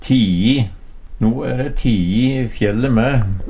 tii - Numedalsmål (en-US)